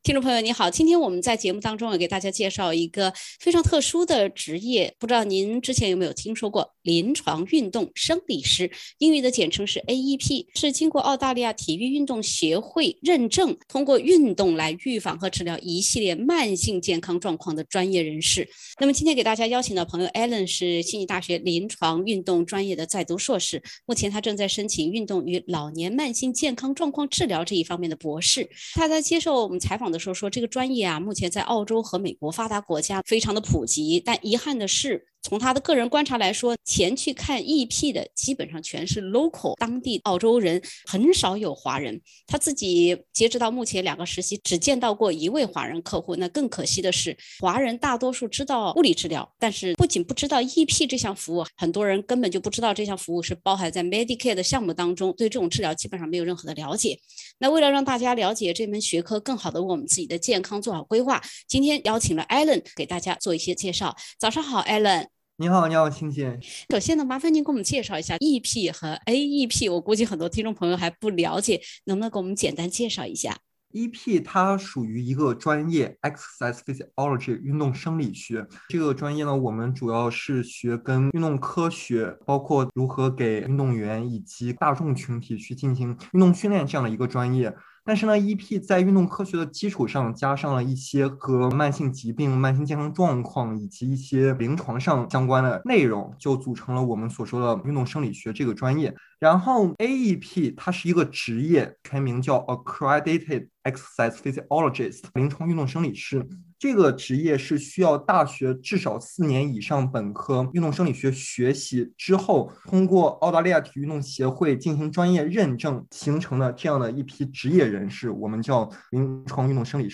包含在Medicare裡的“EP”治療，您知道是個什麼項目，能給我們提供哪些醫療支持，併且知道如何使用嗎？（點擊封面圖片，收聽完整寀訪）